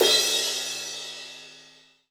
HI-HAT - 12.wav